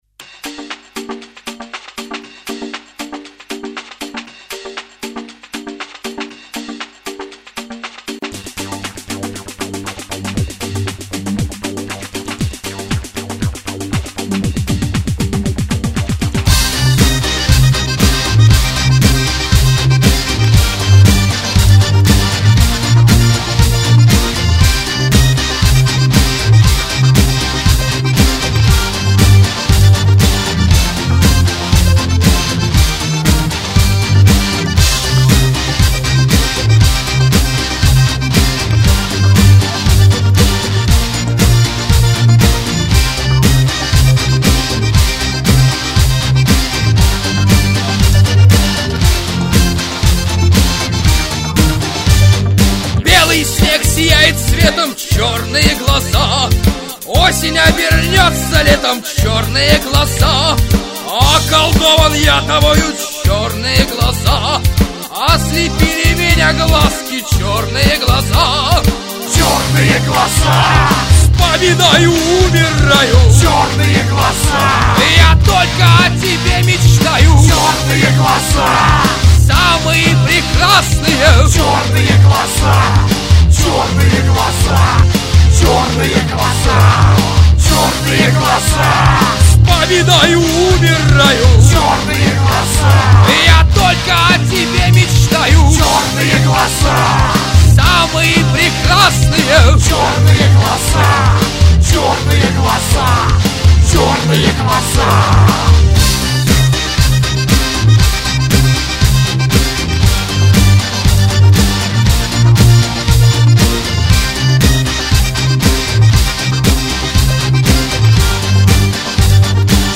Музыкальный хостинг: /Панк